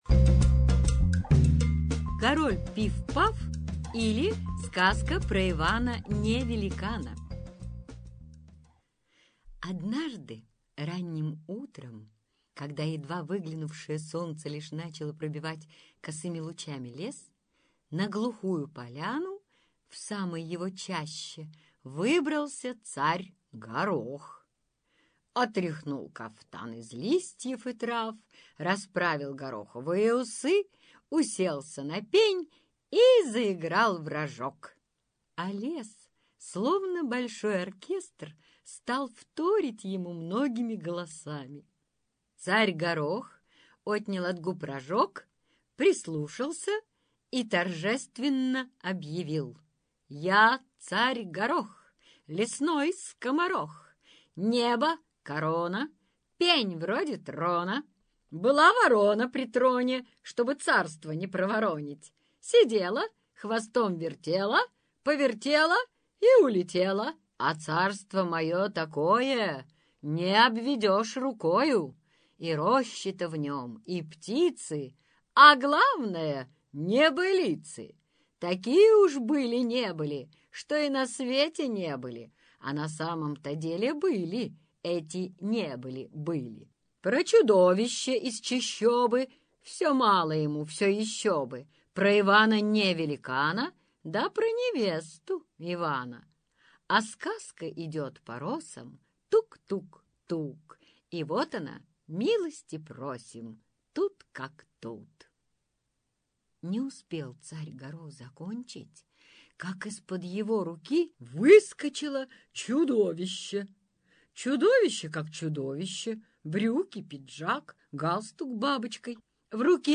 Король Пиф-Паф или Сказка про Ивана-Не-Великана - аудиосказка Вадима Коростылёва - слушать онлайн